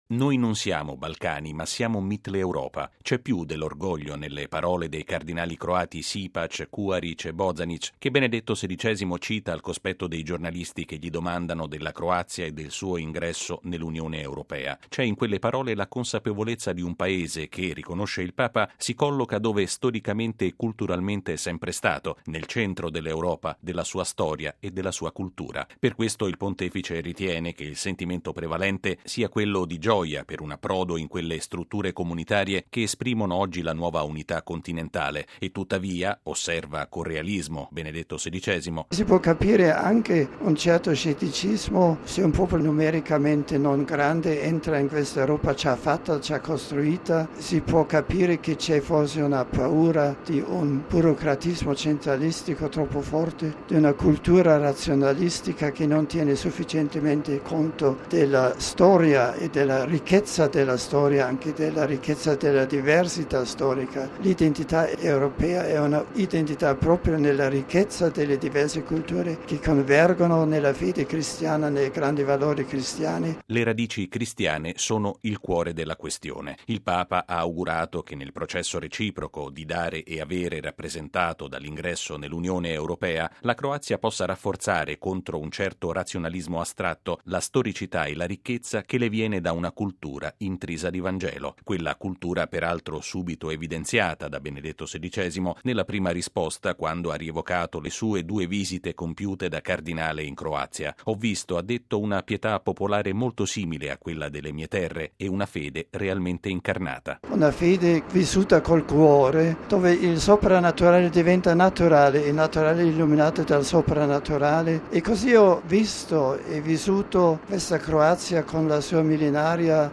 Sono le due immagini che stamattina Benedetto XVI ha regalato della gente croata, durante il suo tradizionale incontro con i giornalisti a bordo del volo diretto a Zagabria. Il Papa ha risposto a tre domande dei cronisti, che gli hanno anche chiesto una riflessione sul Beato cardinale Stepinac.